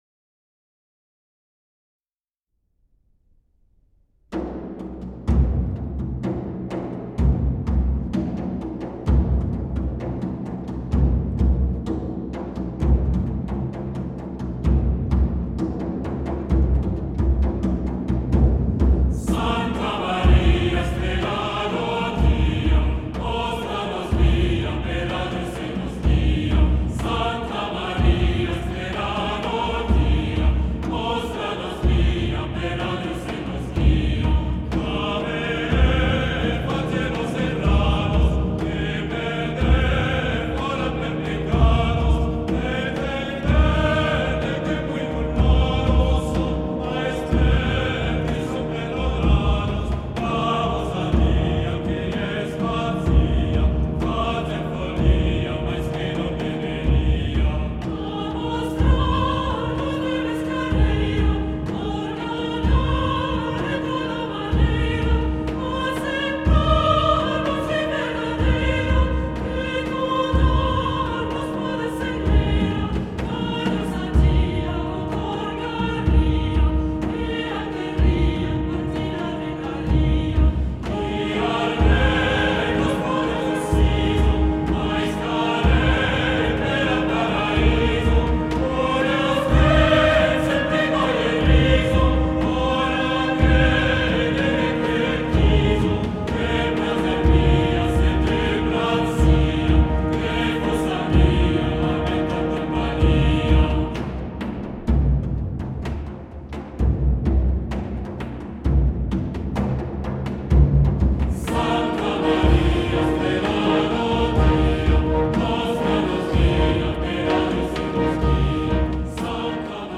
Le disque s’ouvre de façon spectaculaire par les belles percussions du cantique Santa Maria, Strela do dio et, selon les pièces, le chant est assuré par le chœur, par des petites formations solistes ou par des solistes, avec ou sans appui de l’ensemble orchestral.
Enregistré en mars 2016 à Notre-Dame du Liban (Paris).